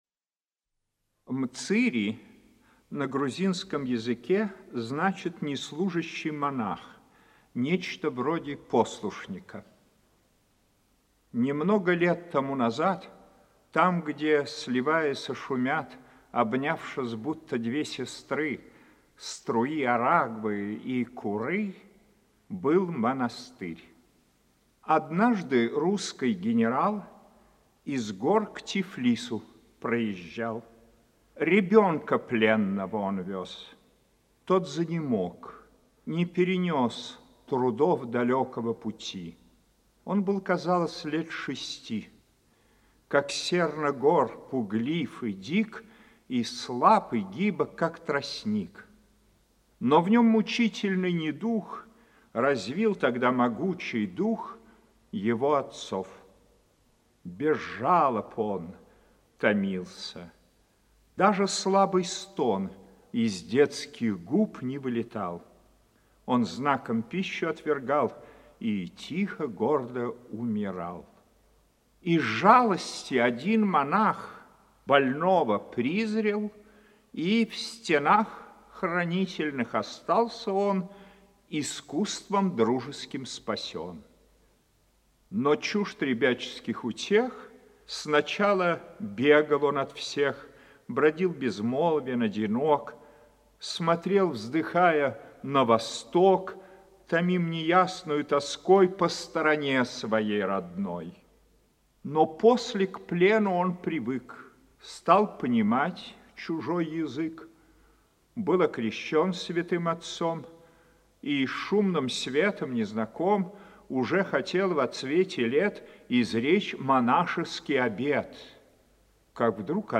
И разве только старый добрый Михал Иваныч Царёв, сам по натуре романтик, смог немного приблизиться к этой тихой элегической атмосфере.